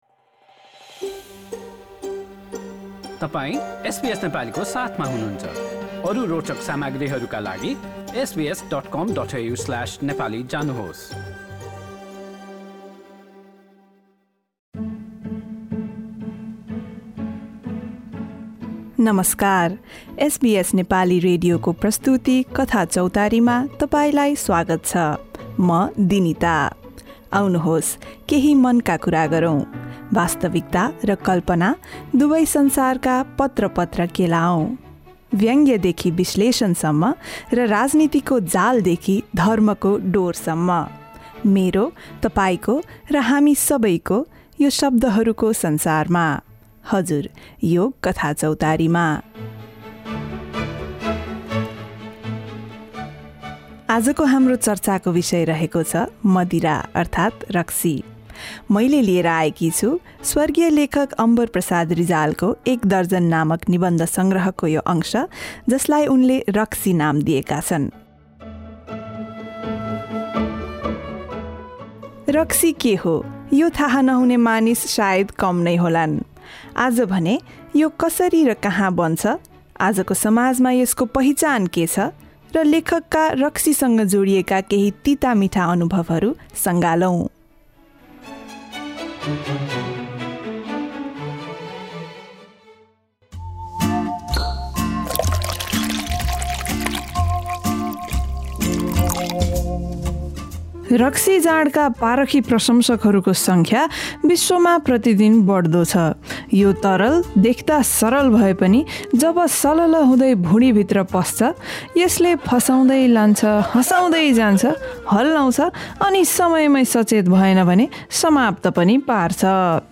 आज हामीले लेखक स्वर्गीय अम्बरप्रसाद रिजालद्वारा रचित "एक दर्जन" निबन्ध सङ्ग्रहको "रक्सी" वाचन गर्दैछौँ।